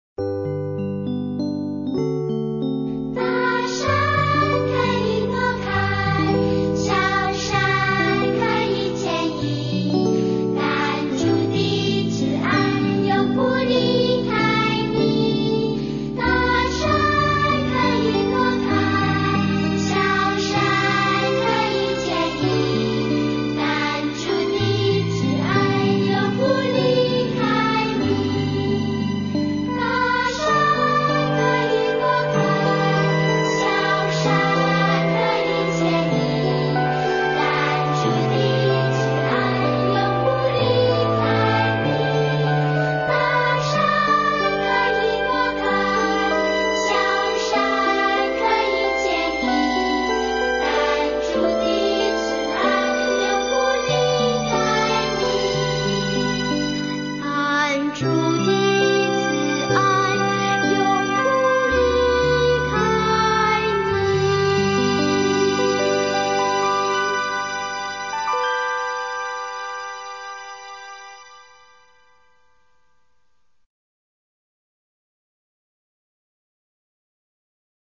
儿童赞美诗《大山可以挪开》